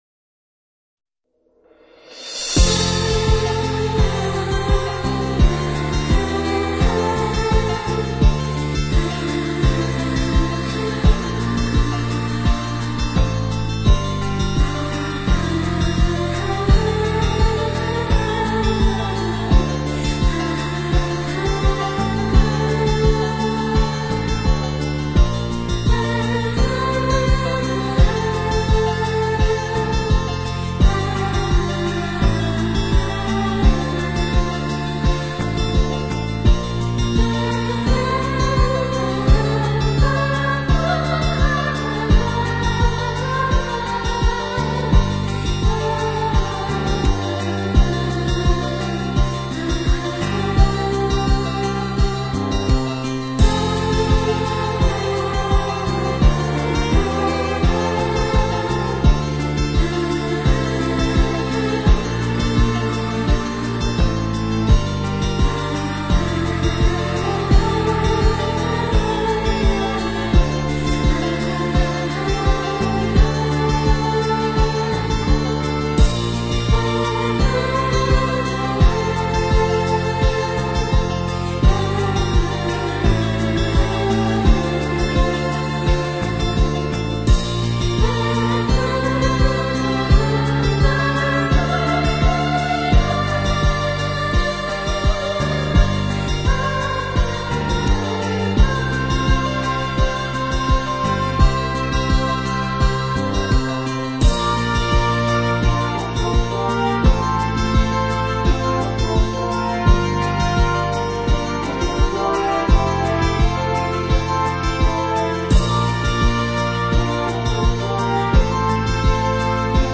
音乐类型 : 日剧电影原声带